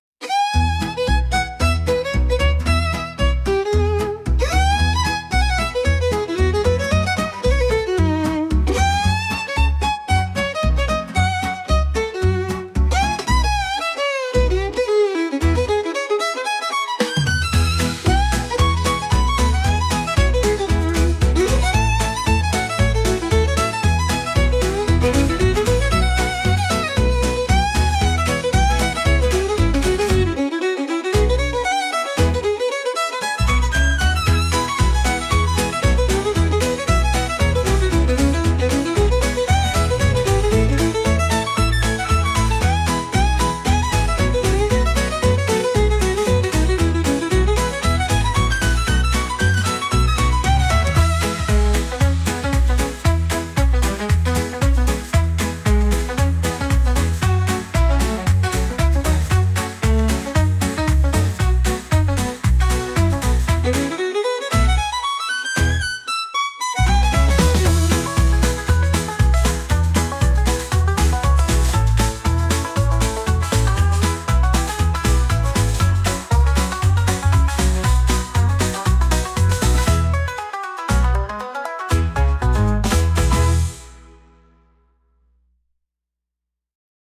Instrumental / 歌なし